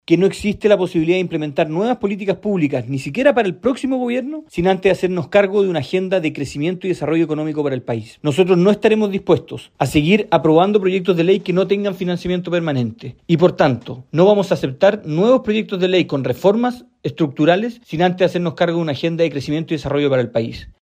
El diputado y jefe de bancada Evopoli, Jorge Guzmán, indicó que en el sector no aceptarán nuevos proyectos de ley con reformas estructurales sin antes acordar una agenda de crecimiento y desarrollo para el país.